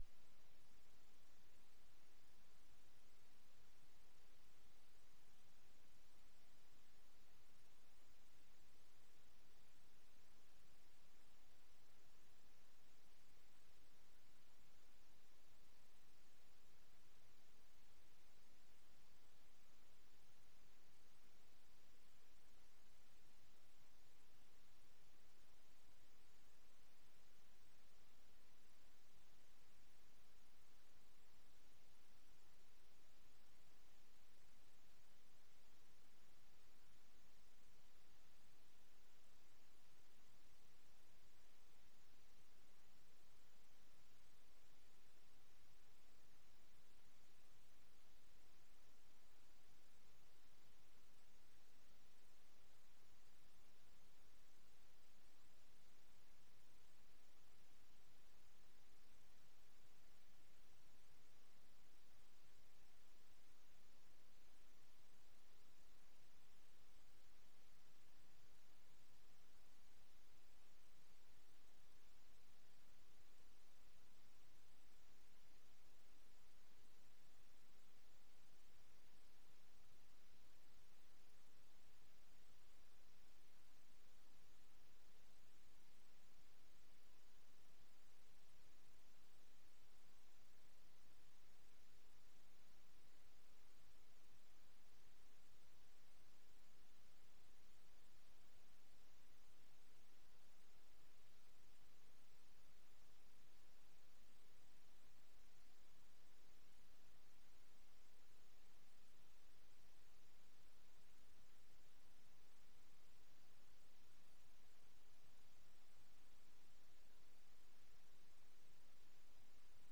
Sermon-7-16-17.mp3